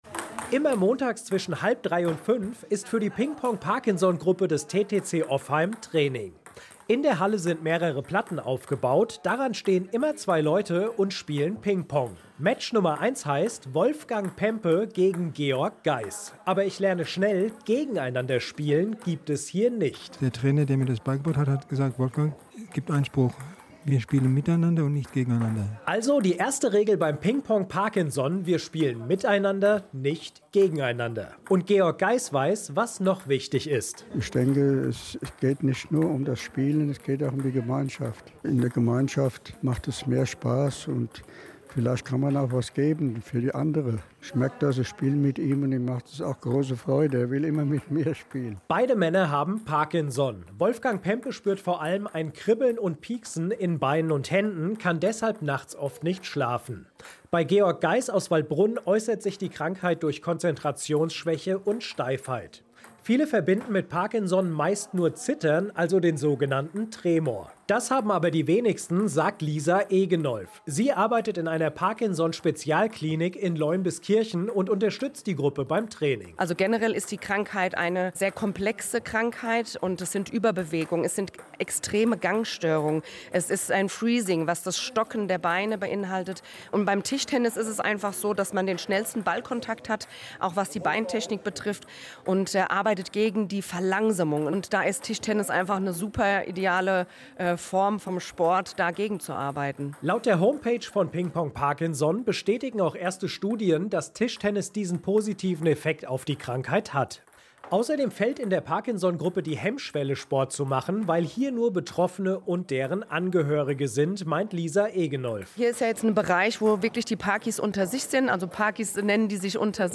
Parkinson Patienten konnten direkt mit dem Reporter sprechen und ihm die positiven Eigenschaften von Tischtennis gegenüber Parkinson schildern.
In den Gesprächen konnte man die Freude an der neuen Gruppe und der sportlichen Betätigung immer wieder heraushören.
Interview mit dem Hessischen Rundfunk